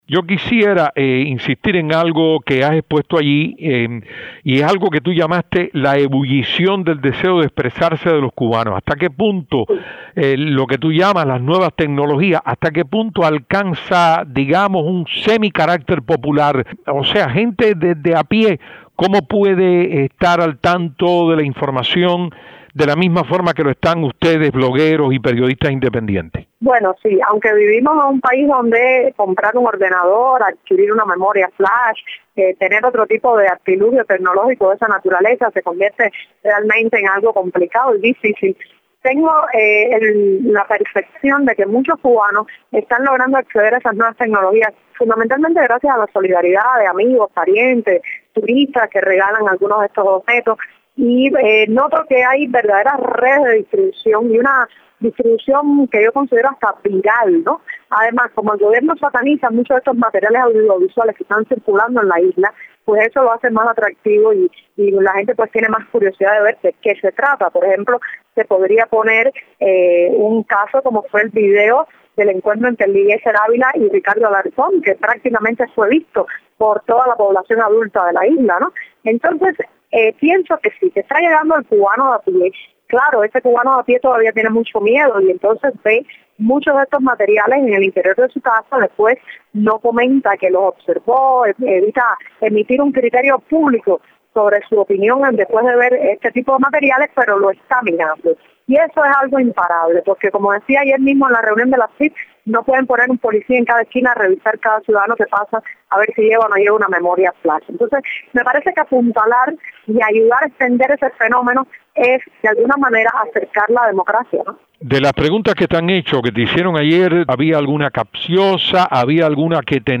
Yoani Sánchez, entrevistada